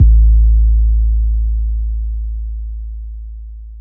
808s
808L6.wav